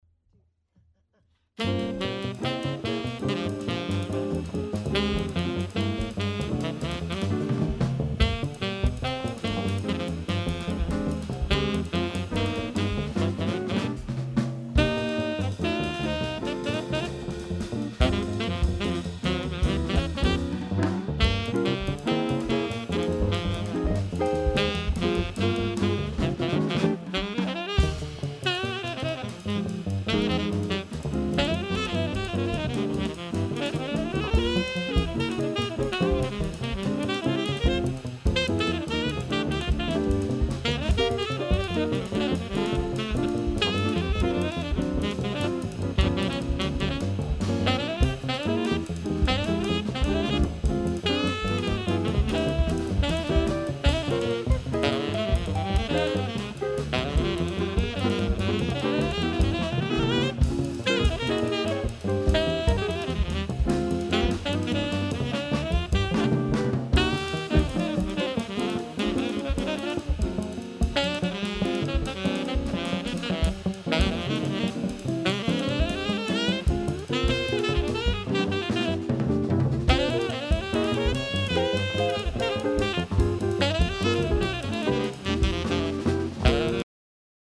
Sax and Vocals
Drums
Bass
Guitar